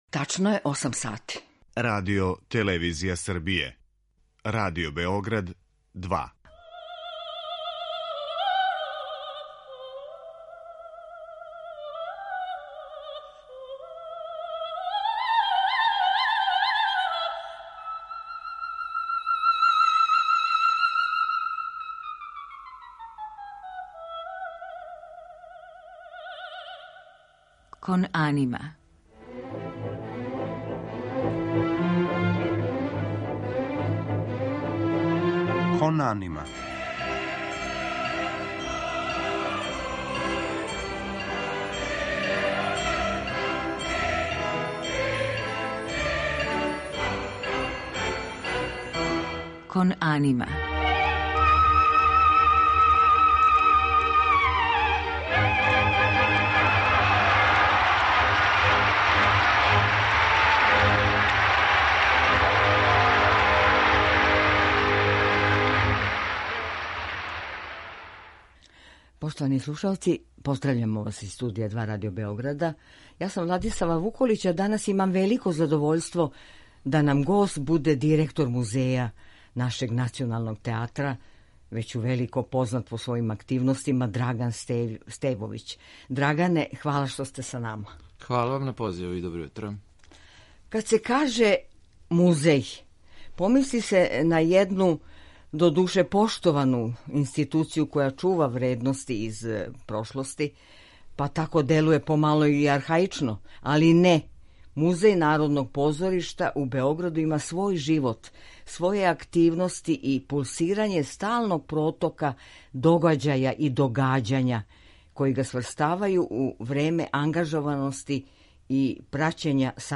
Говориће о раду и многобројним активностима ове важне установе. У музичком делу чућете оперске арије у извођењу наших најпознатијих вокалних уметника из прошлих дана.